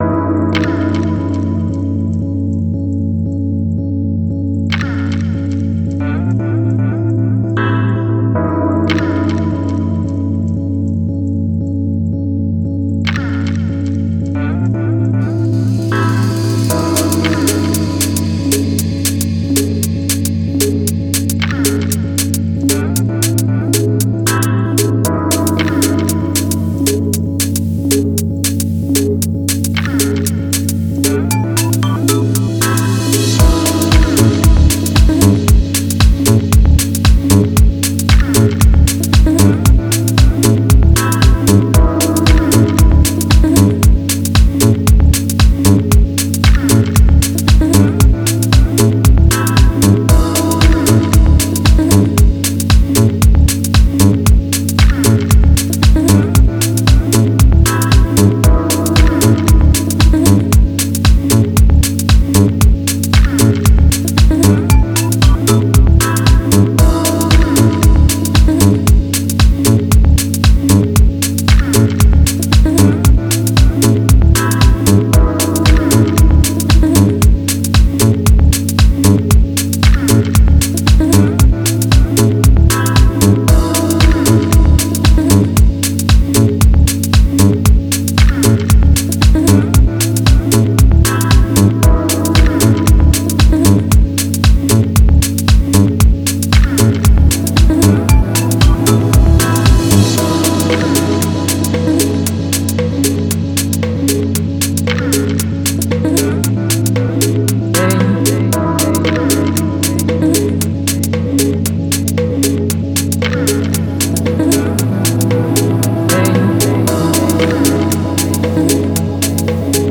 Genre: Deep House/Tech House.